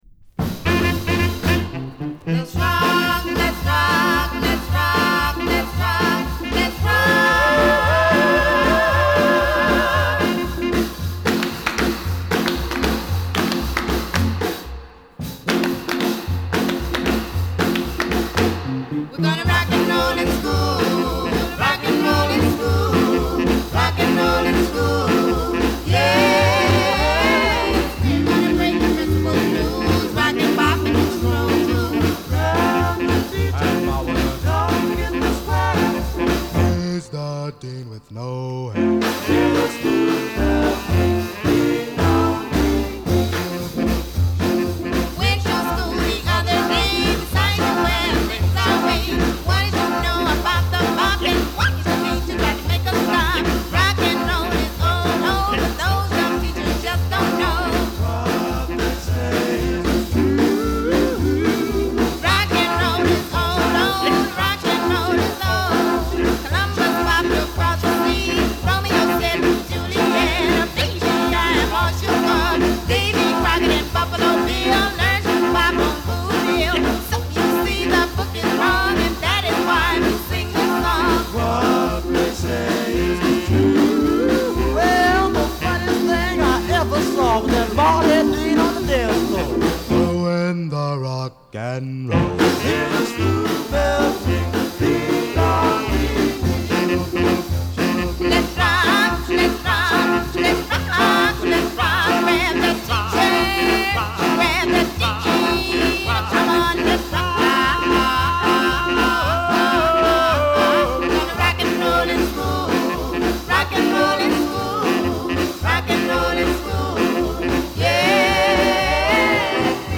B面はティーン・ロッカーを収録。